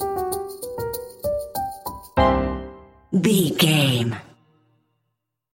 Uplifting
Aeolian/Minor
flute
oboe
strings
orchestra
cello
double bass
percussion
silly
goofy
cheerful
perky
Light hearted
quirky